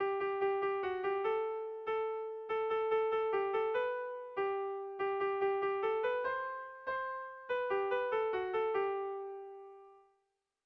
Air de bertsos - Voir fiche   Pour savoir plus sur cette section
Kontakizunezkoa
Lauko txikia (hg) / Bi puntuko txikia (ip)
AB